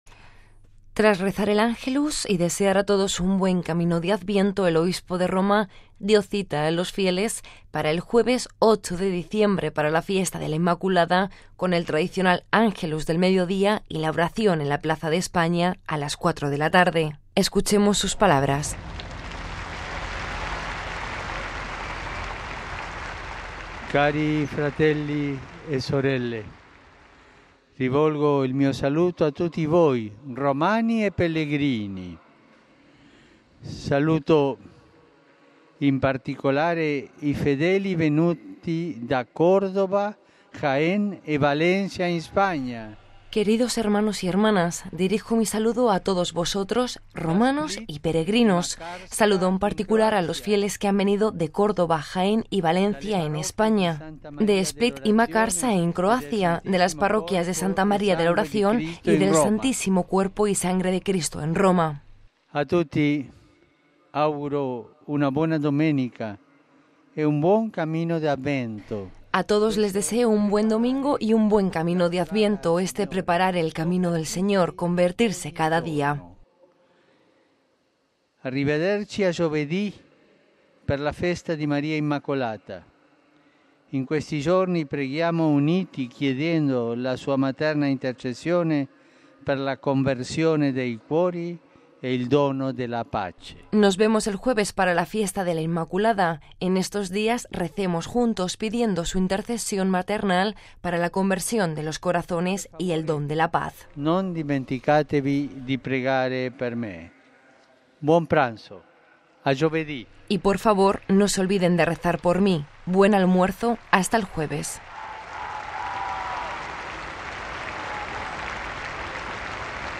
Audio y texto Saludos del Papa despues del ángelus